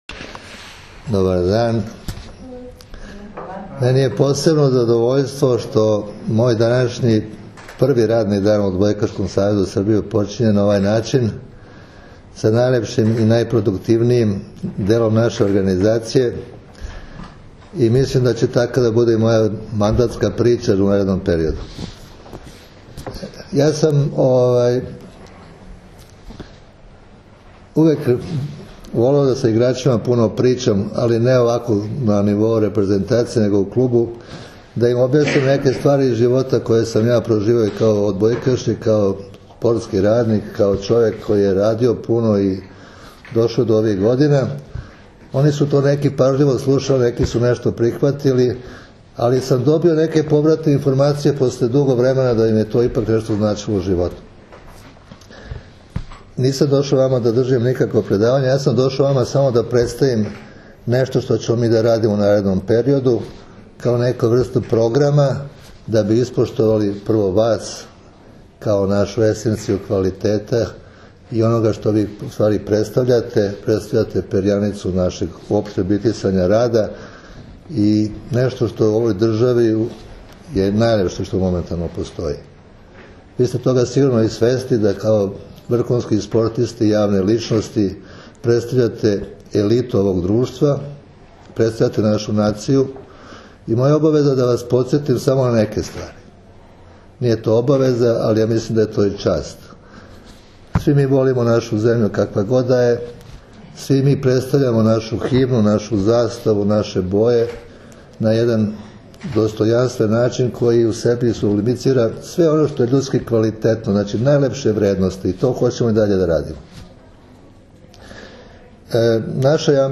OBRAĆANJE